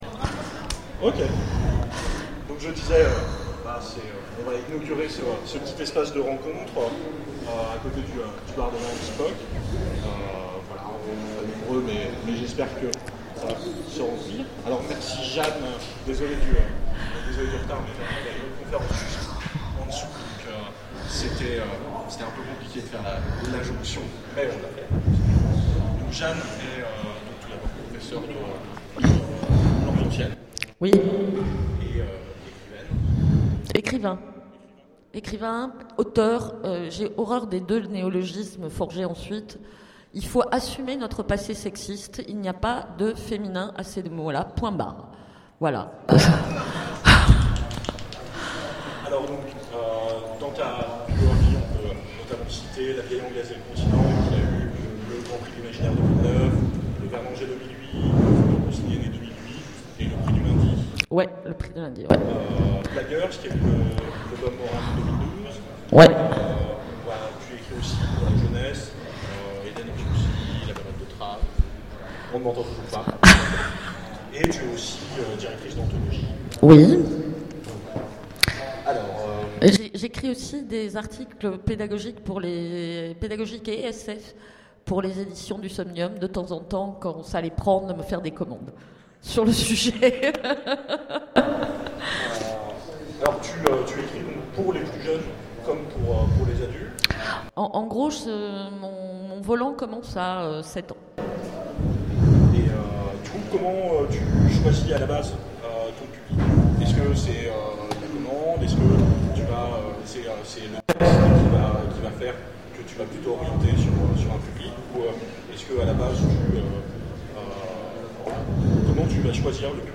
Utopiales 13
Conférence
Mots-clés Rencontre avec un auteur Conférence Partager cet article